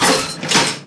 trapdooropen.wav